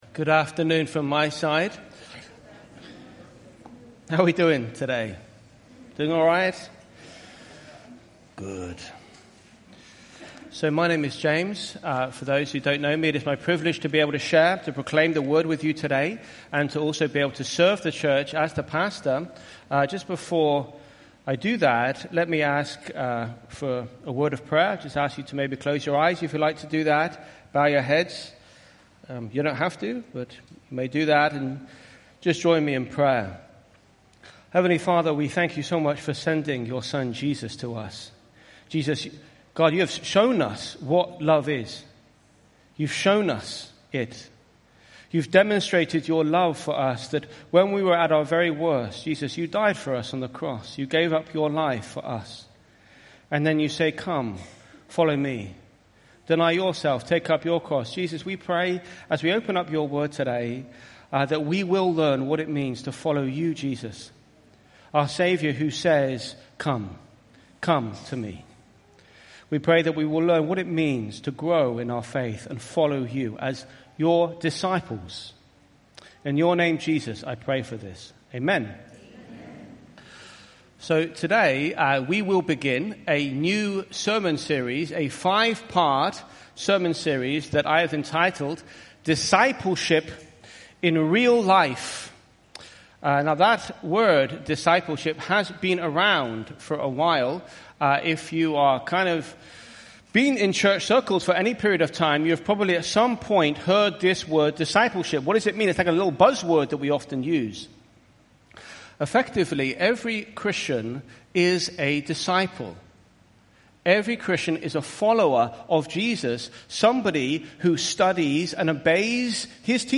IBC Hamburg Predigt